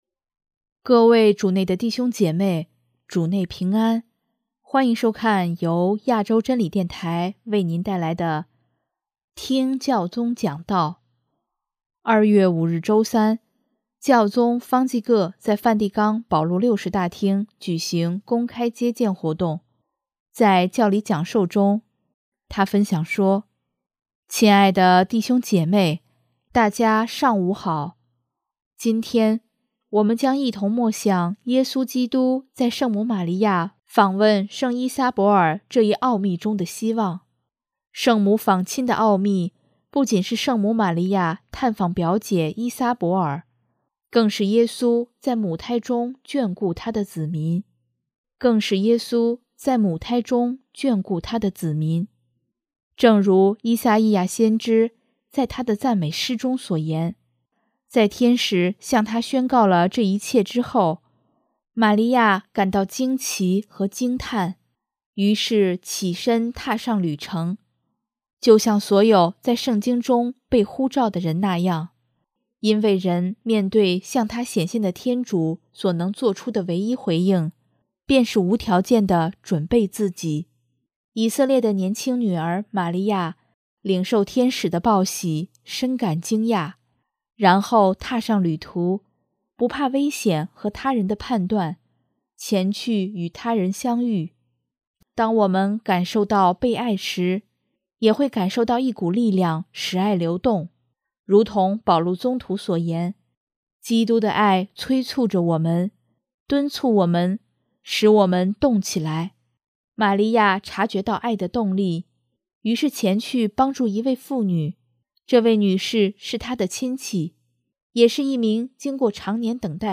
2月5日周三，教宗方济各在梵蒂冈保禄六世大厅举行公开接见活动，在教理讲授中，他分享说：